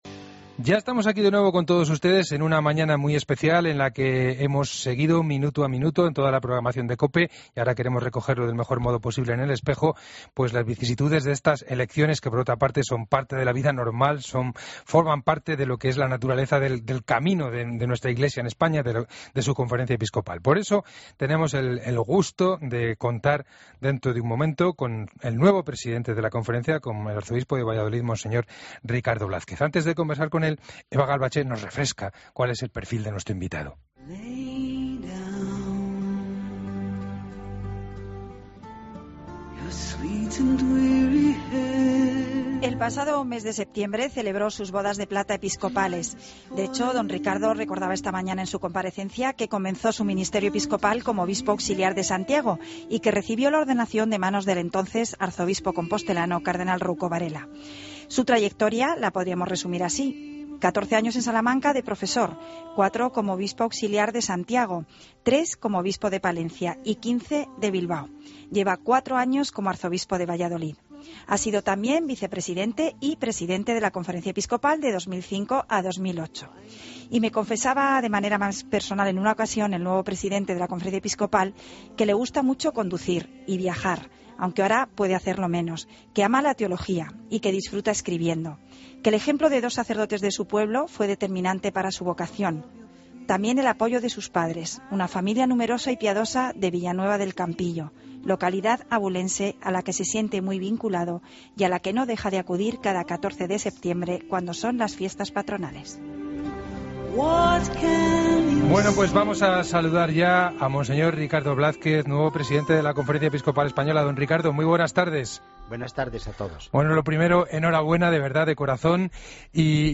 Escucha la entrevista completa a monseñor Ricardo Blázquez en 'El Espejo' de COPE